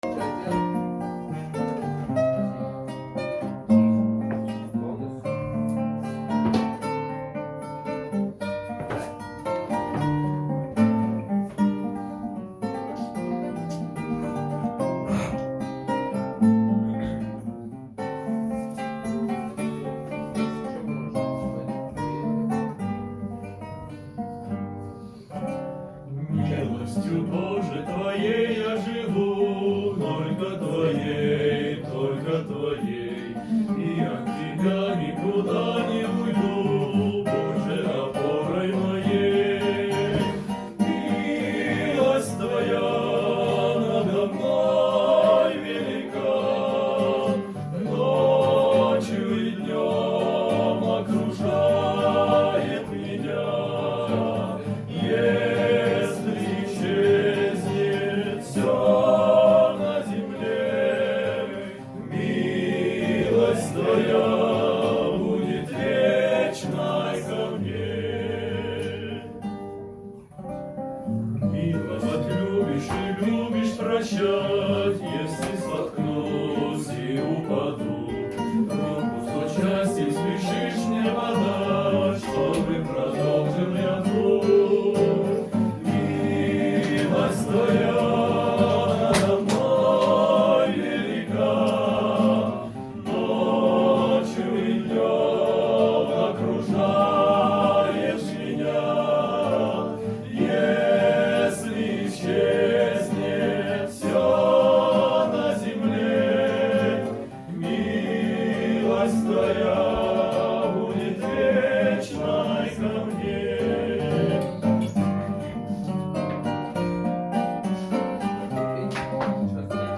203 просмотра 161 прослушиваний 11 скачиваний BPM: 80